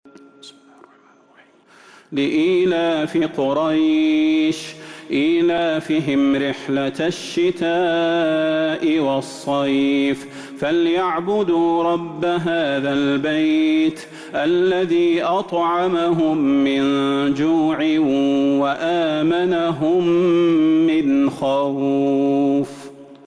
سورة قريش Surat Quraysh من تراويح المسجد النبوي 1442هـ > مصحف تراويح الحرم النبوي عام 1442هـ > المصحف - تلاوات الحرمين